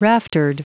Prononciation du mot raftered en anglais (fichier audio)
Prononciation du mot : raftered